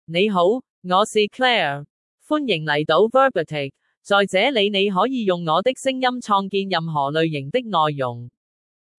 FemaleChinese (Cantonese, Hong Kong)
ClaireFemale Chinese AI voice
Voice sample
Female
Claire delivers clear pronunciation with authentic Cantonese, Hong Kong Chinese intonation, making your content sound professionally produced.